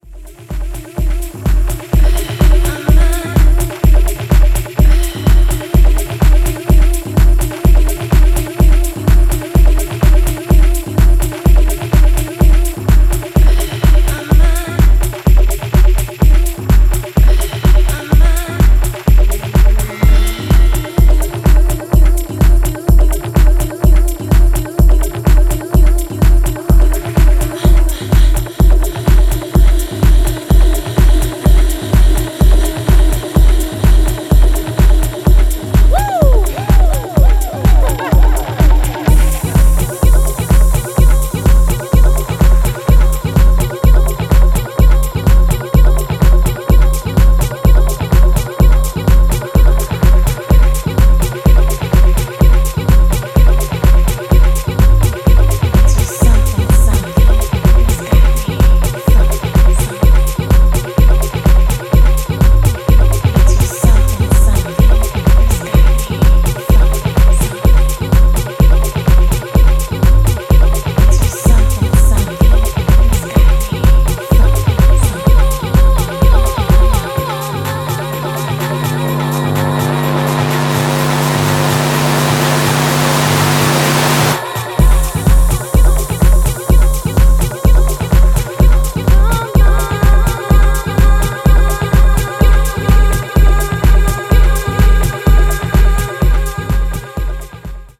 has produced three percussive and ready-to-use tracks!